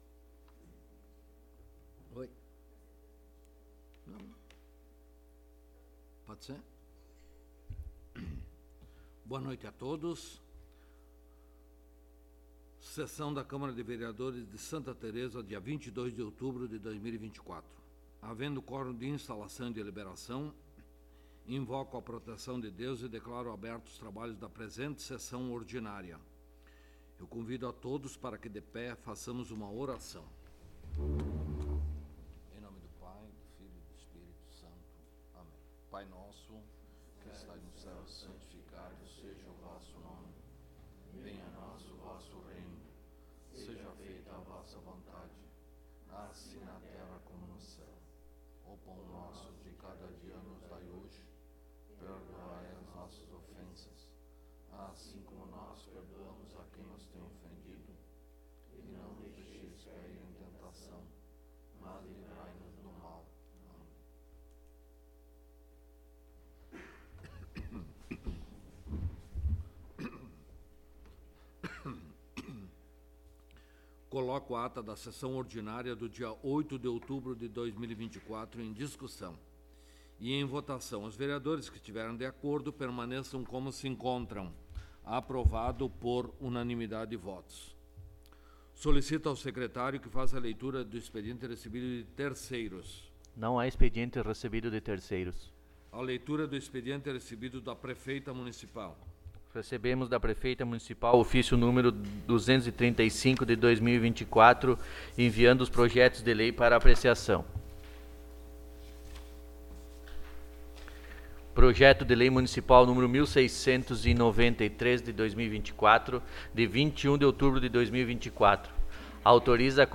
18ª Sessão Ordinária de 2024
Local: Câmara Municipal de Vereadores de Santa Tereza
Áudio da Sessão